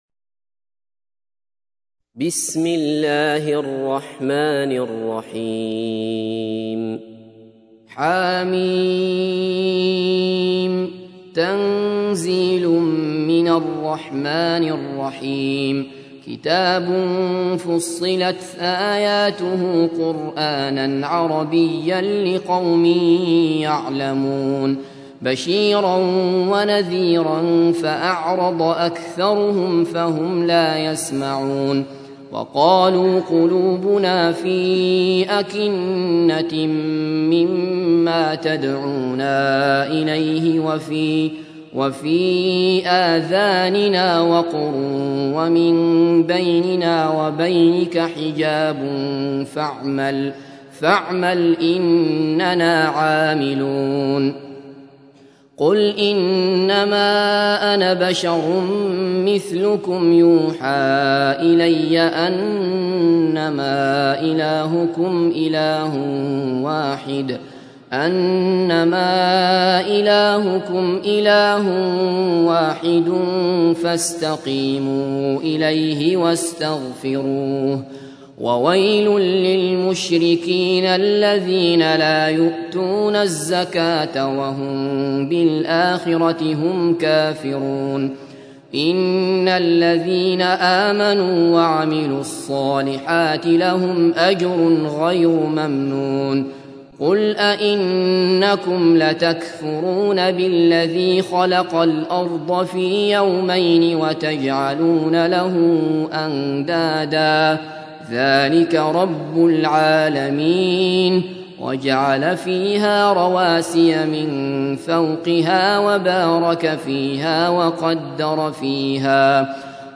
تحميل : 41. سورة فصلت / القارئ عبد الله بصفر / القرآن الكريم / موقع يا حسين